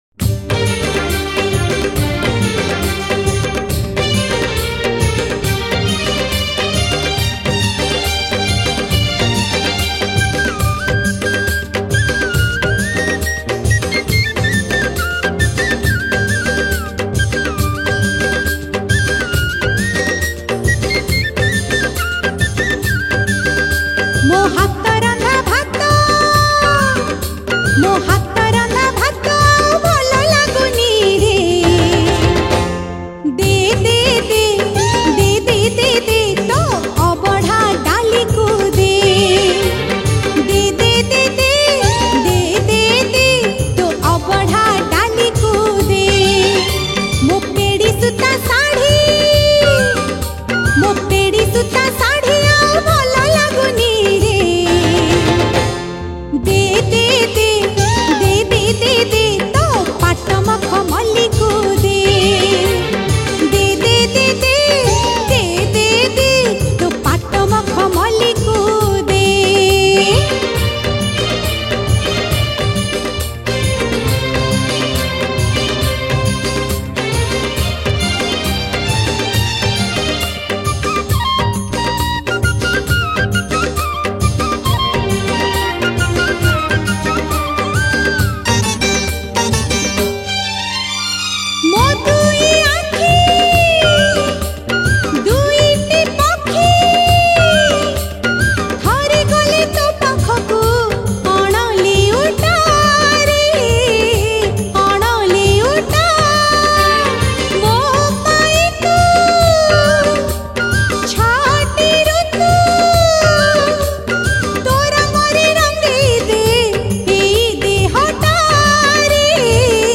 Ratha Yatra Odia Bhajan